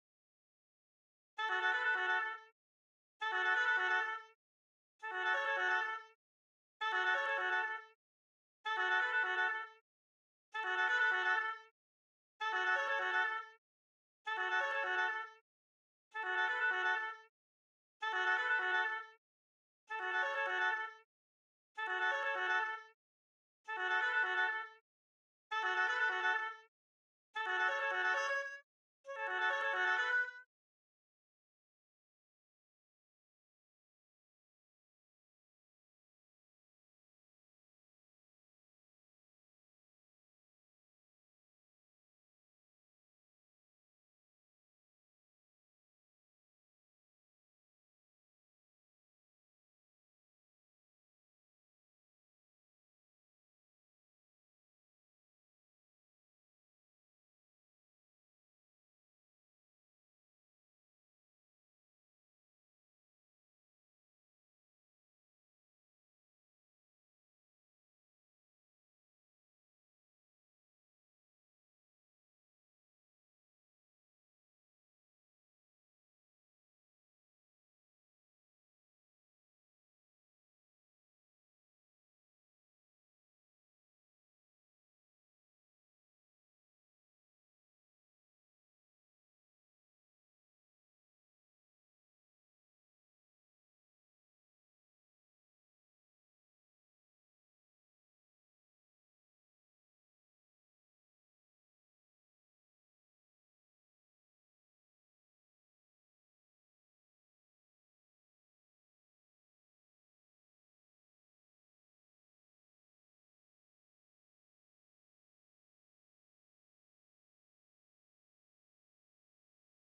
6. Oboe 2 (Oboe/Normal)
RecantationGrounds-30-Oboe_2.mp3